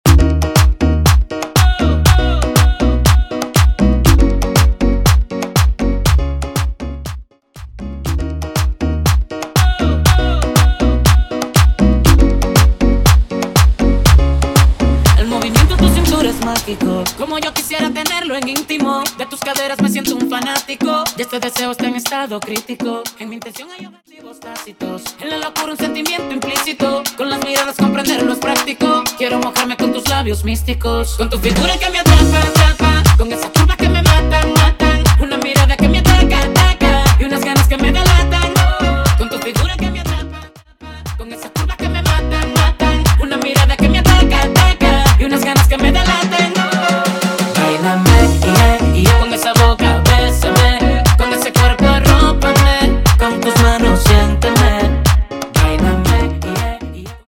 Latin genres